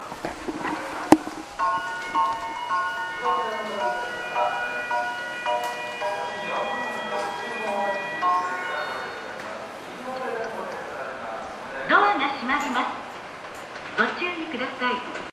しかし音量が少し小さいと思います(特に２番線)。
２番線JM：武蔵野線